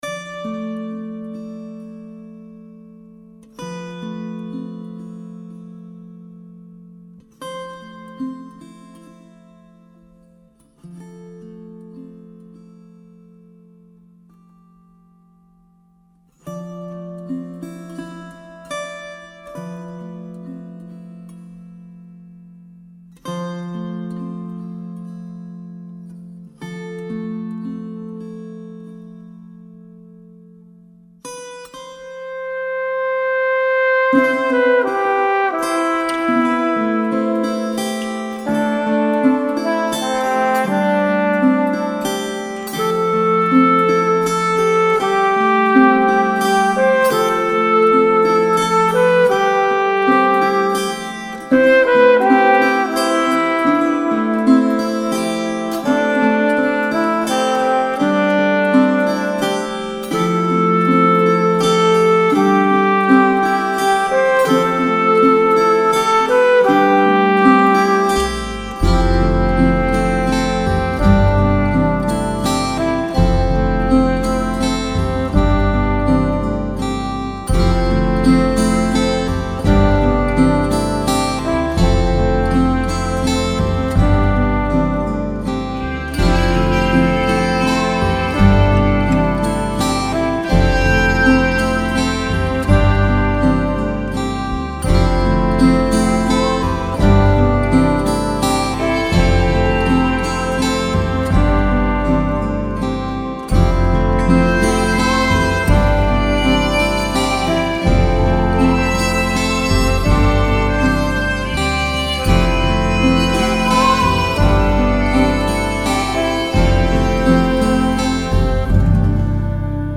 flugelhorn or trumpet